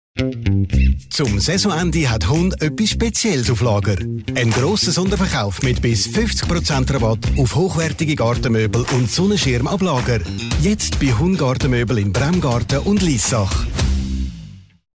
Radiospot
Radiospot_EVIVA_HUNN_GARTENMÖBEL_AG.mp3